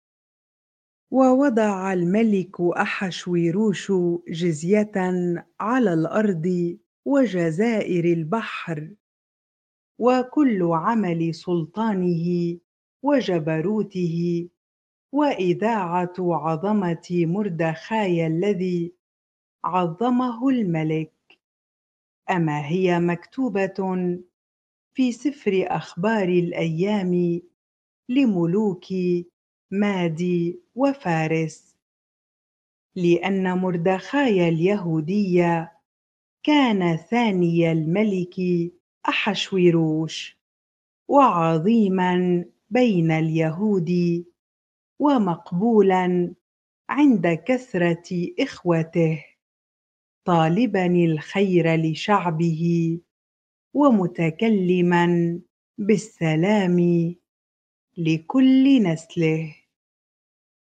bible-reading-Esther 10 ar